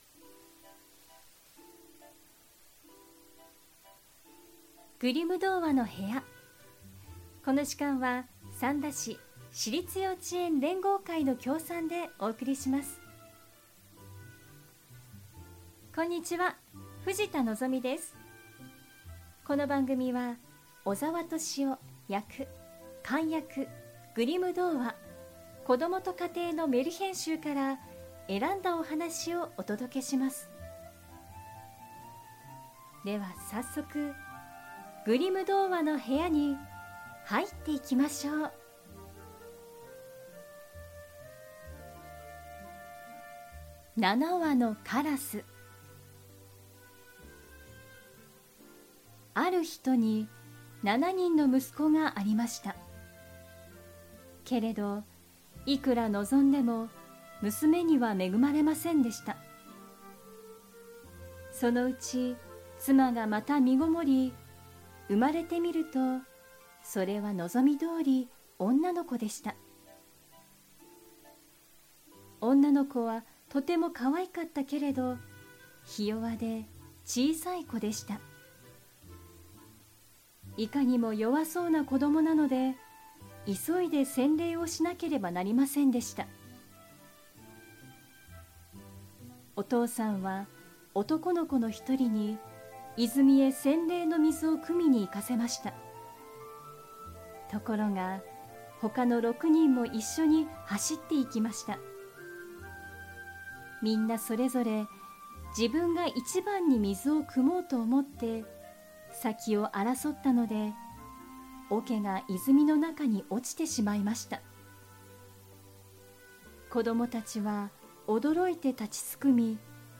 グリム兄弟によって集められたメルヒェン（昔話）を、翻訳そのままに読み聞かせします📖 今回お届けするのは『七羽のカラス』。 父親のひとことでカラスに姿を変えられてしまった7人の兄を妹が救いに行くお話です📖 ごく短編ですが、様々な気持ちを感じながら読み進められます。